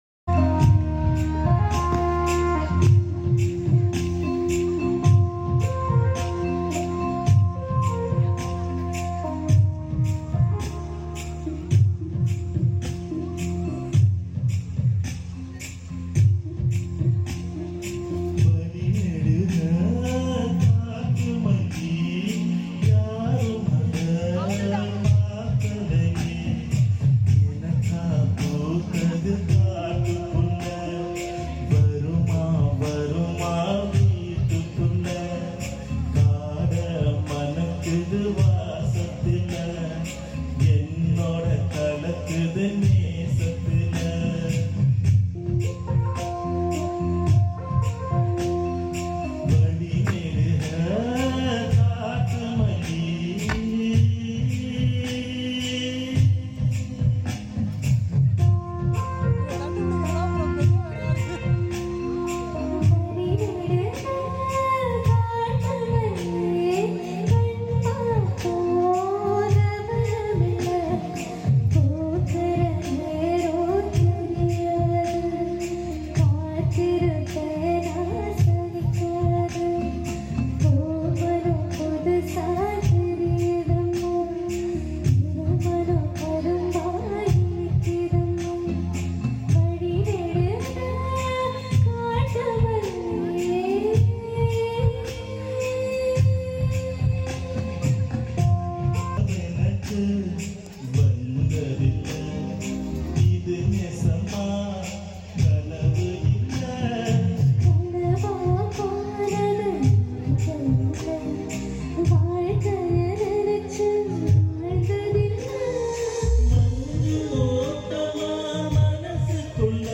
song in Tanjavur, Tamil Nadu on 15 March 2025